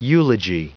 Prononciation du mot eulogy en anglais (fichier audio)
Prononciation du mot : eulogy